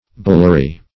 Bullary \Bul"la*ry\, n. [LL. bullarium: cf. F. bullairie. See